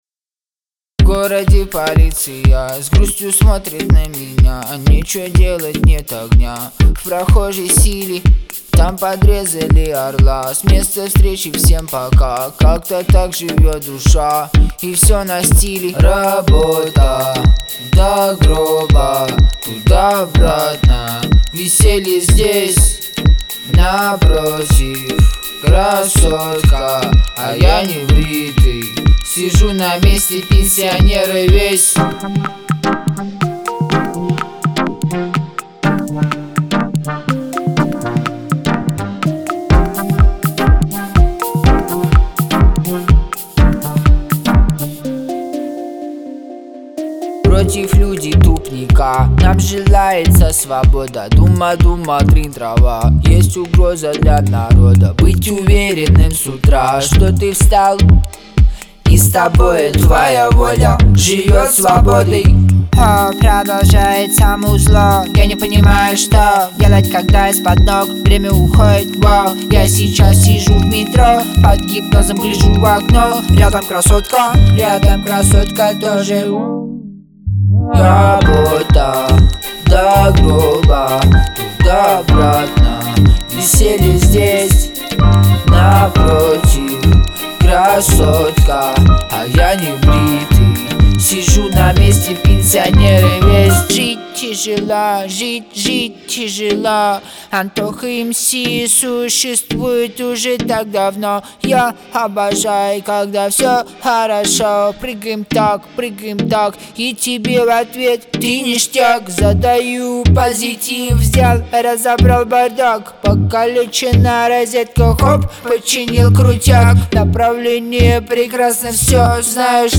это динамичная композиция в жанре хип-хоп